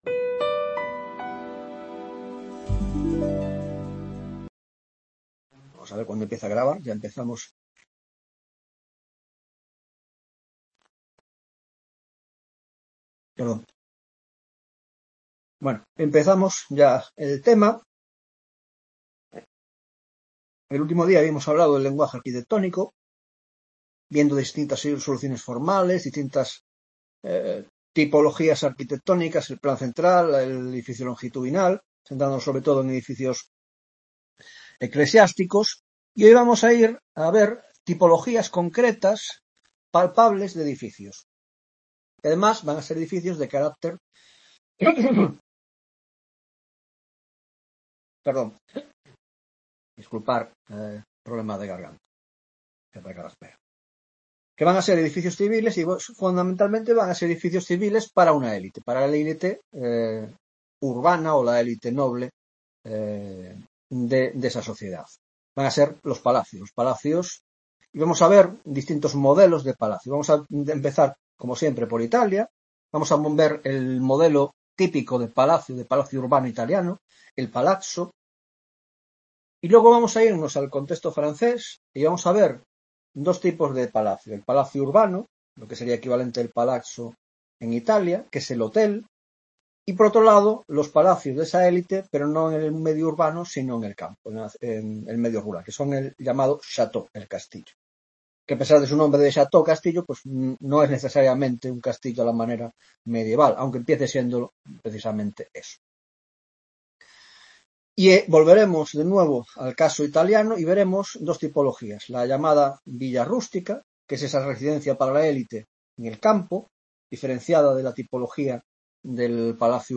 7ª Tutoria de Órdenes y Espacio en la Arquitectura Moderna (grado de Historia del Arte): Tipologías: El Palacio Urbano (1ª parte): El Palazzo: 1) Introducción: 1.1) Palacio Urbano, Villa Rústica y Villa Suburbana: 1.2) Origen y contexto de la aparición del Palazzo; 2) El Palazzo y el modelo florentino; 3) Variantes y evolución del modelo florentino; 4) El Modelo Veneciano de Palazzo; 5) El Palacio Barroco y la apertura de la arquitectura palacial al entorno urbano